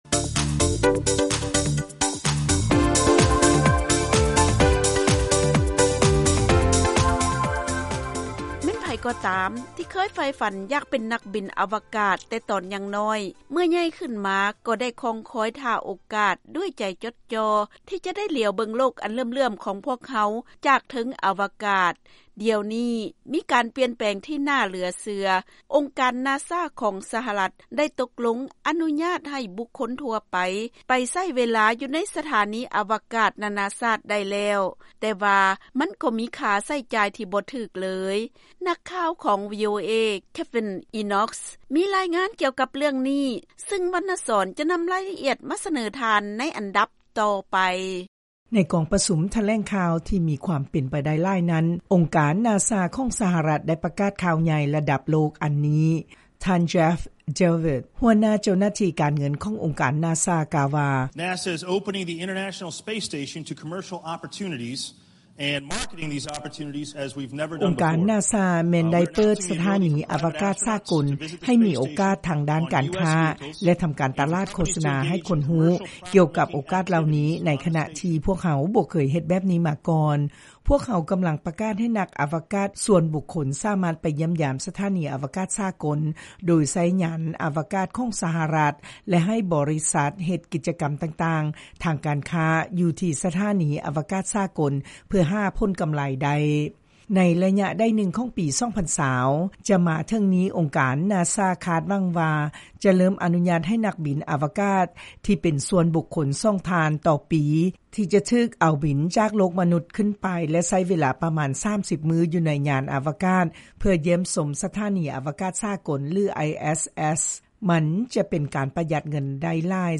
ລາຍງານກ່ຽວກັບ ອົງການນາຊາອະນຸຍາດໃຫ້ນັກບິນອາວະກາດສ່ວນບຸກຄົນ ເດີນທາງໄປຍັງສະຖານີອາວະກາດນາໆຊາດແລ້ວ